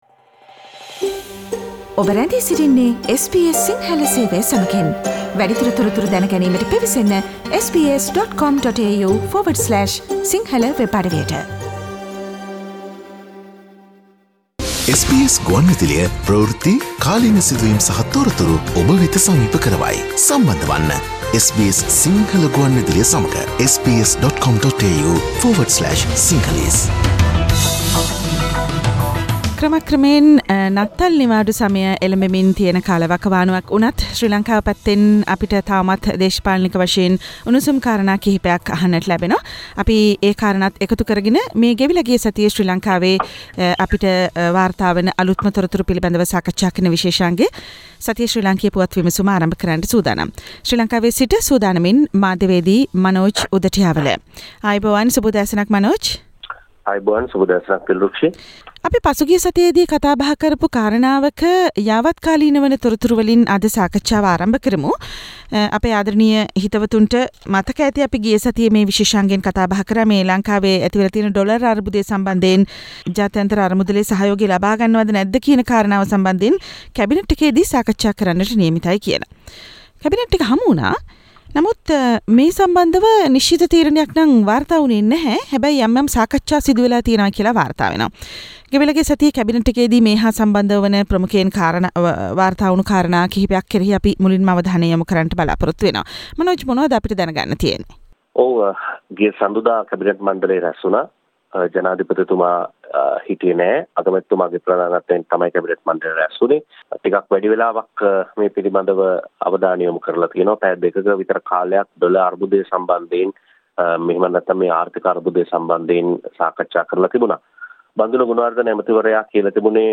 පී බී ඉල්ලා අස්වීම දෙයි. ආණ්ඩුව ඇතුලෙන්ම මෙච්චර විරෝධයක් තියෙද්දිත් ජනපති ඝෝටා එය පිලි නොගනියි: ශ්‍රී ලංකා පුවත් විමසුම